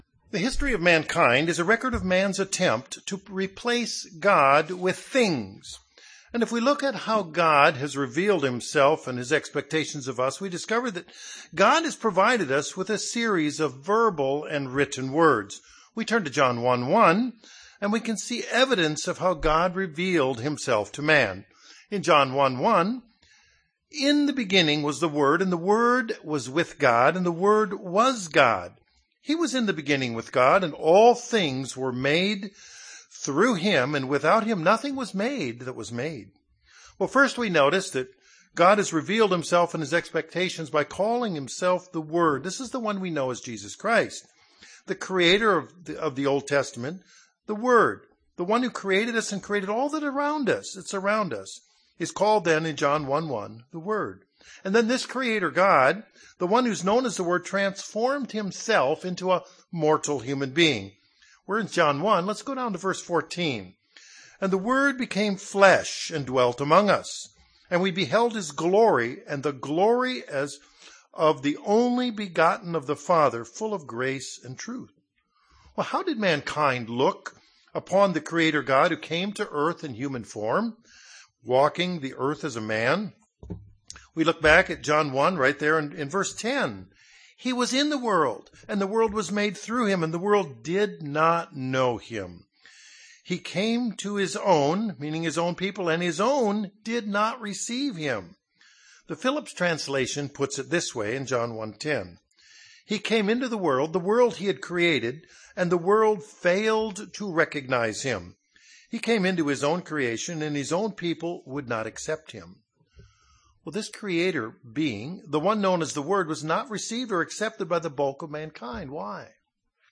Given in Northwest Arkansas
UCG Sermon Studying the bible?